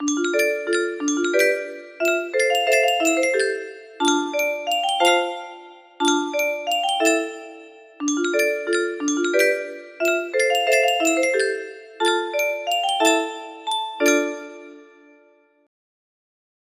A short arrangement